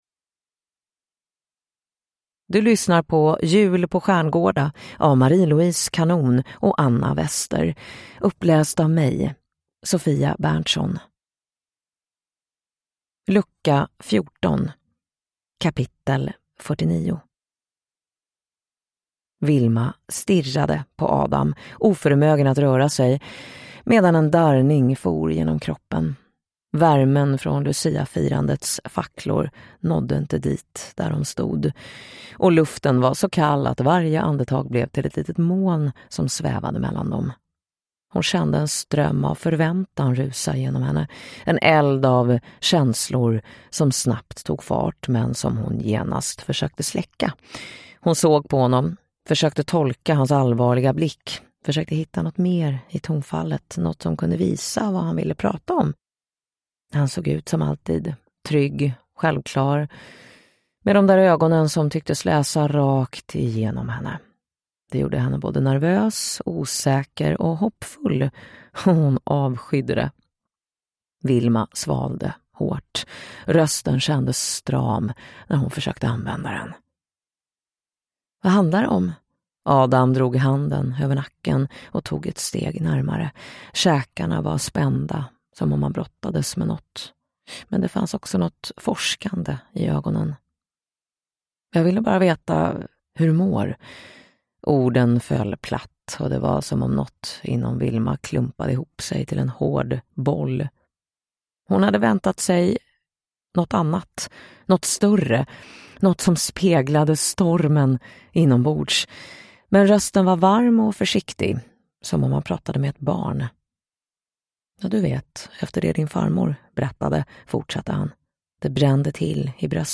Jul på Stjärngårda: Lucka 14 – Ljudbok